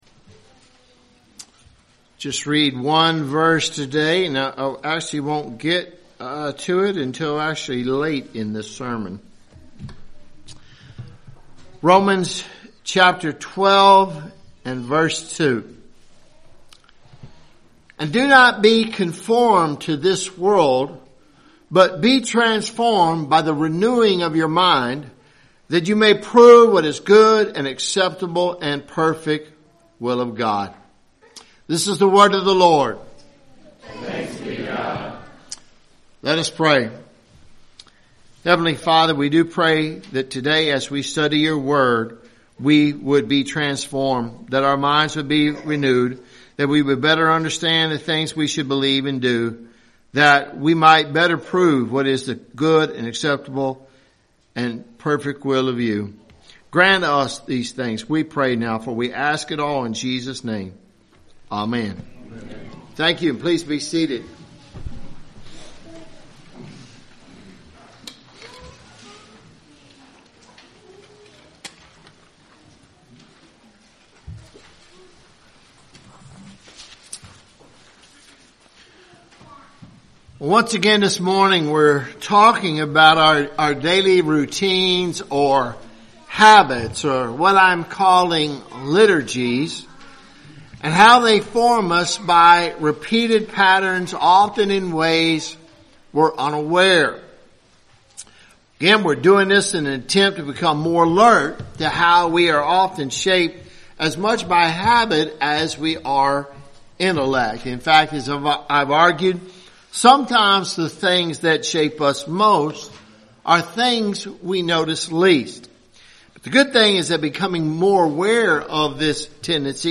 1-March-2026-sermon.mp3